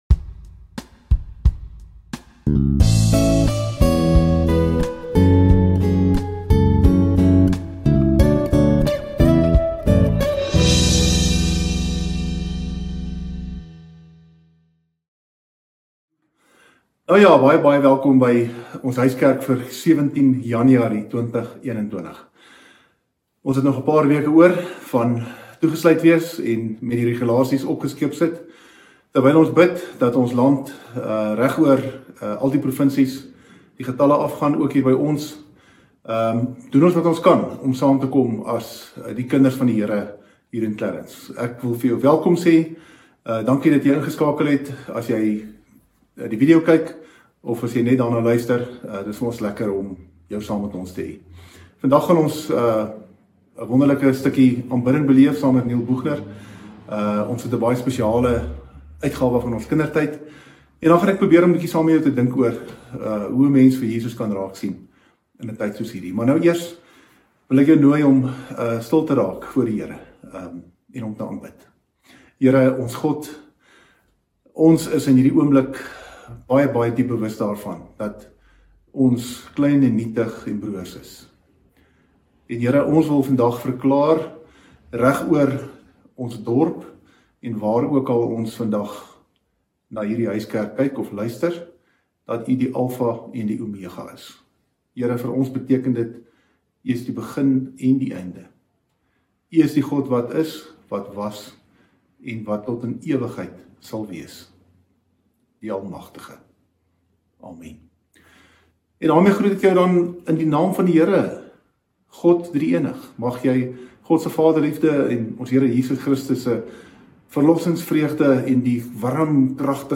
Huiskerk by Clarens Gemeente